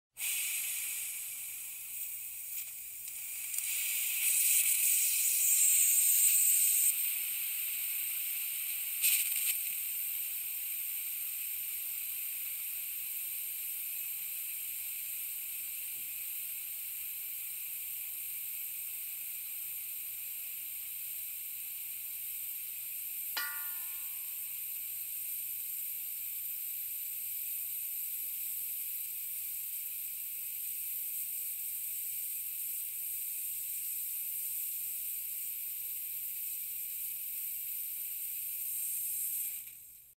Звуки утечки газа
Аудиофайлы передают характерное шипение, свист и другие звуки, сопровождающие утечку бытового или природного газа.